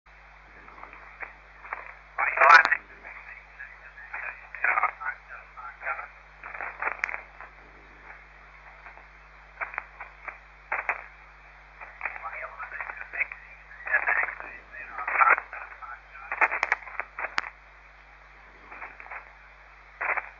AUDIO SSB SAMPLE (only for fun) ATF54143 biased by local lightnings.
The preamplifier was supplied by lightning sparks :)